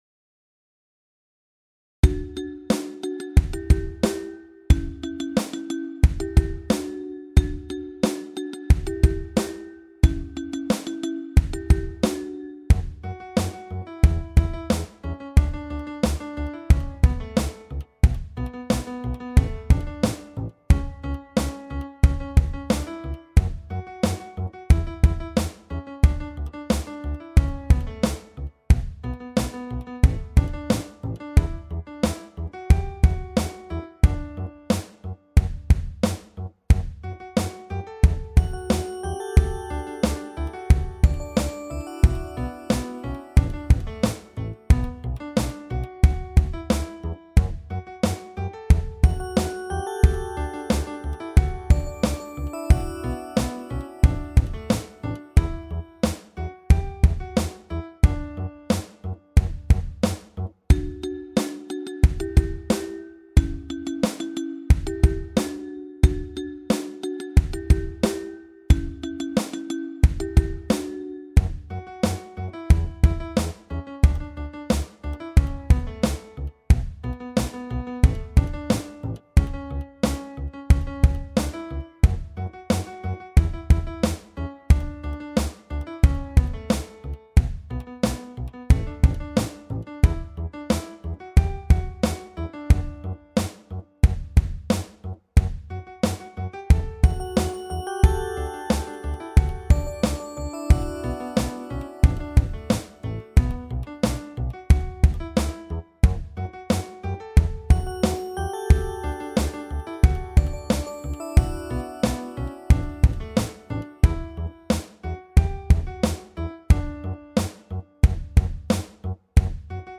伴奏　　オルゴールバージョン
take4_banso.mp3